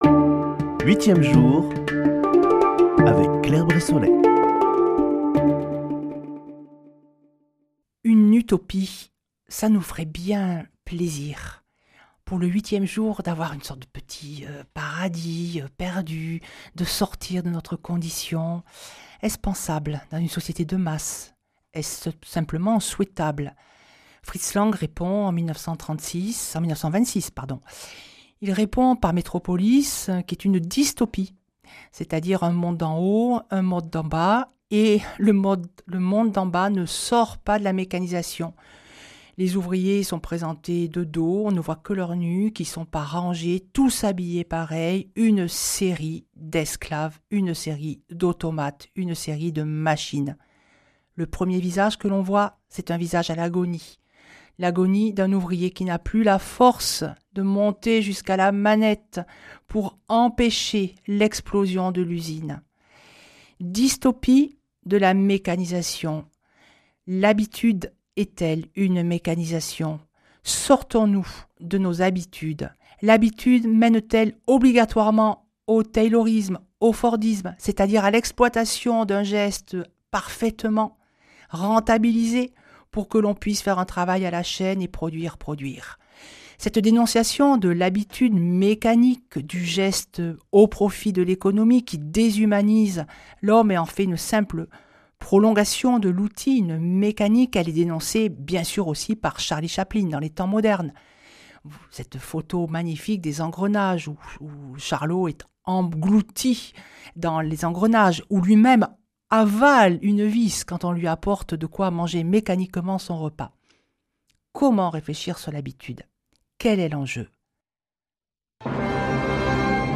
Une émission présentée par
Chroniqueuse